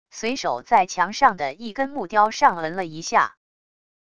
随手在墙上的一根木雕上嗯了一下wav音频